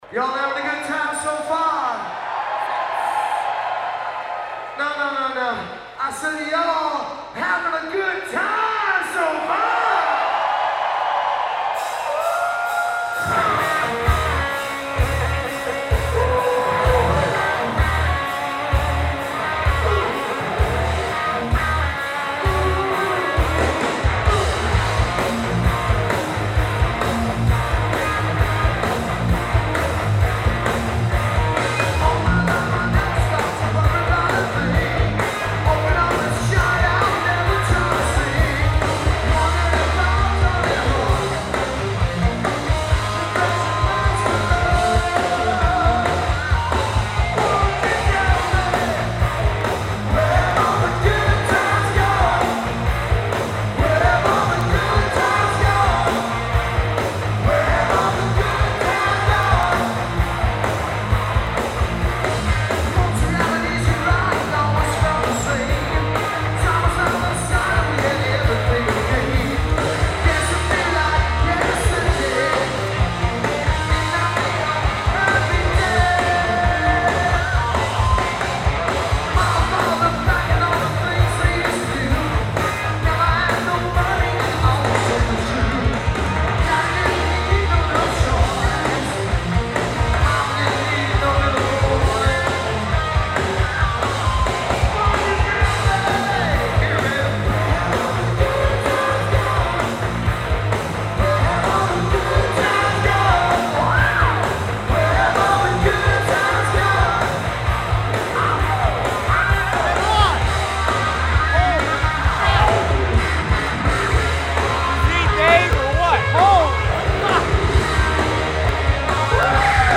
Genre: Hard Rock.